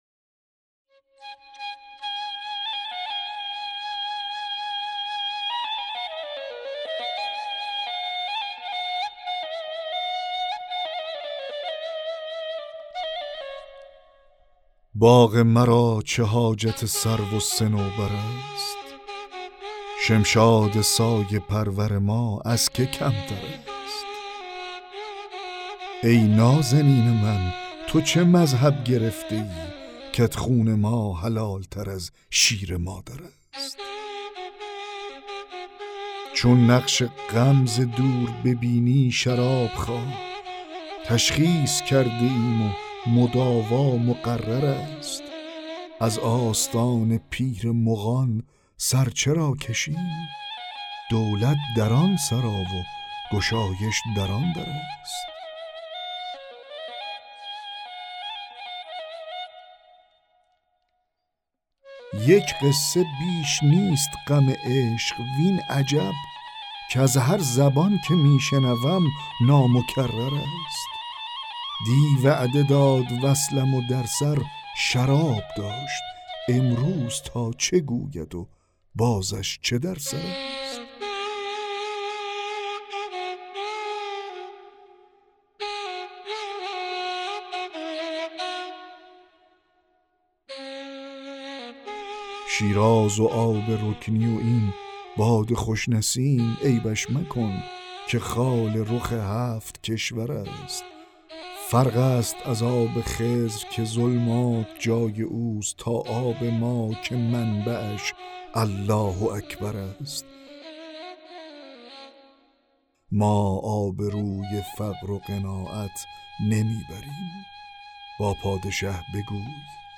دکلمه غزل 39 حافظ
دکلمه-غزل-39-حافظ-باغ-مرا-چه-حاجت-سرو-و-صنوبر-است.mp3